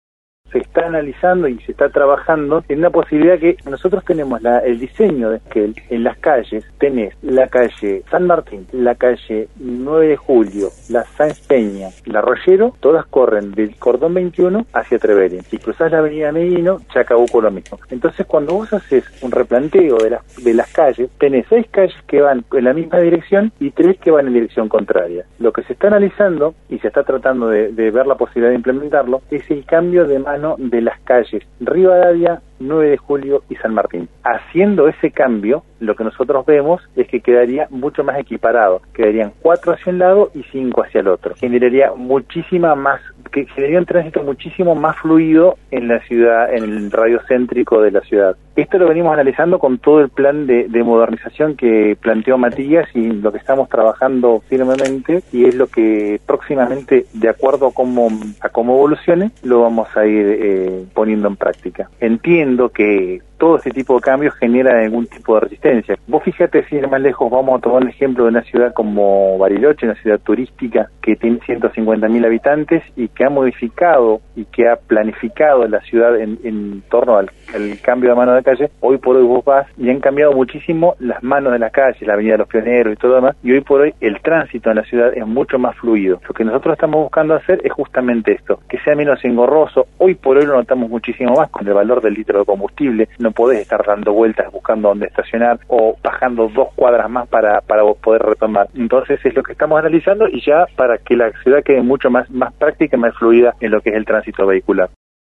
El Director de Transporte de la Municipalidad de Esquel, Diego Austin, en diálogo con el programa Postales de Radio que se emite por Nacional Esquel, adelantó que se analiza la posibilidad de cambiar el sentido de circulación de las calles San Martín, 9 de Julio y Rivadavia. El funcionario expresó que sería en el marco del plan de modernización de la ciudad, con el objetivo de hacer más fluido el tránsito en la zona céntrica de Esquel.